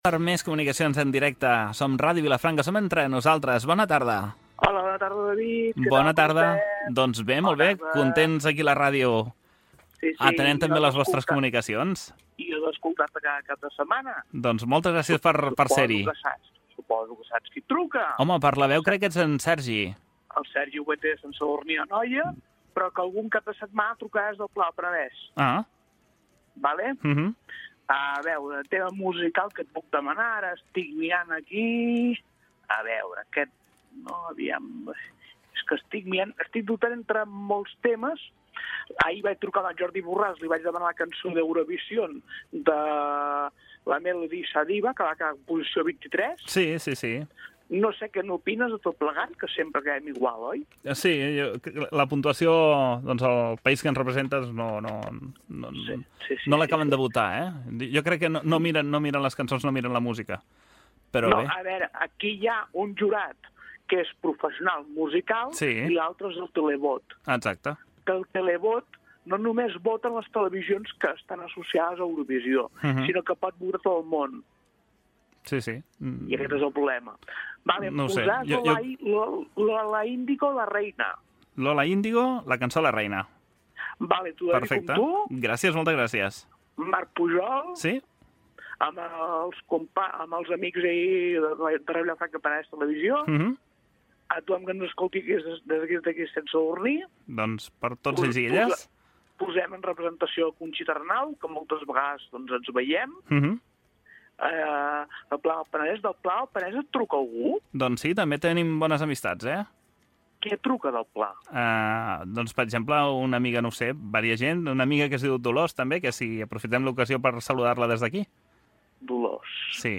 Identificació del programa i l'emissora, trucada telefònica sobre el Festival d'Eurovisió, per saludar i per demanar un tema musical
Entreteniment
FM